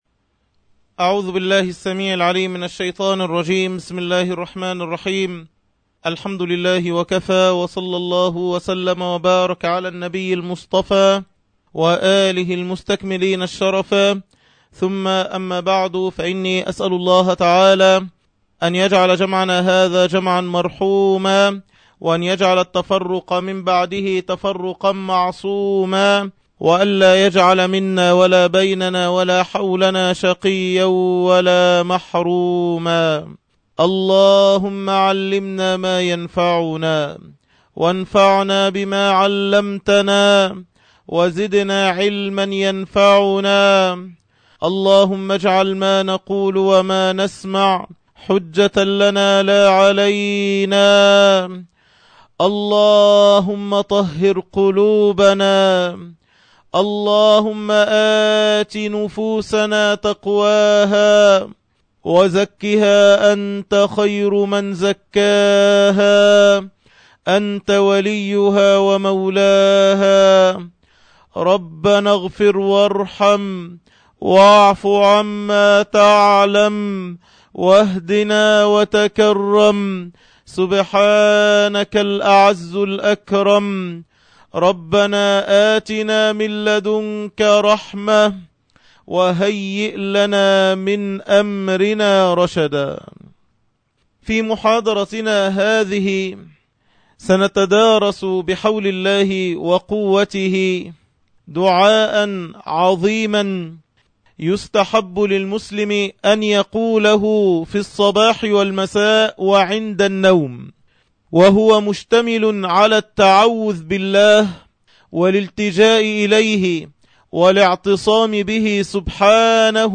عنوان المادة الدرس الثاني عشر(شرح الاذكار) تاريخ التحميل السبت 30 يونيو 2012 مـ حجم المادة 15.70 ميجا بايت عدد الزيارات 1,427 زيارة عدد مرات الحفظ 554 مرة إستماع المادة حفظ المادة اضف تعليقك أرسل لصديق